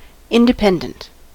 independent: Wikimedia Commons US English Pronunciations
En-us-independent.WAV